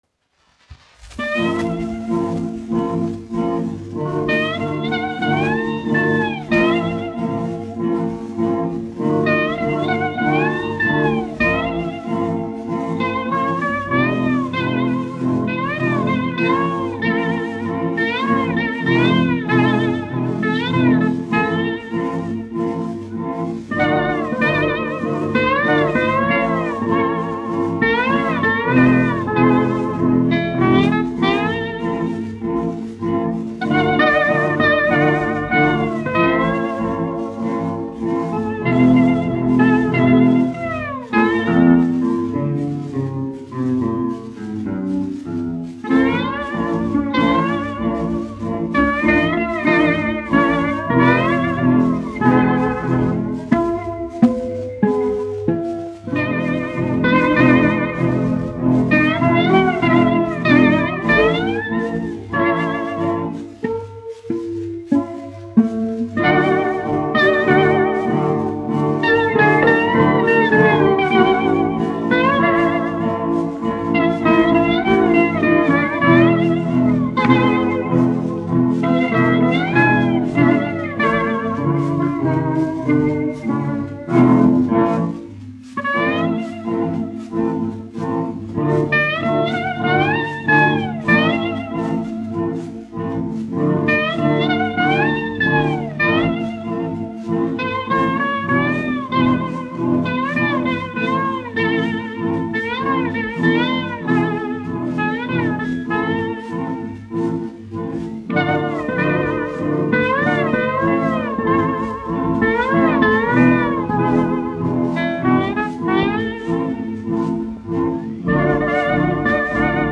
1 skpl. : analogs, 78 apgr/min, mono ; 25 cm
Populārā instrumentālā mūzika
Skaņuplate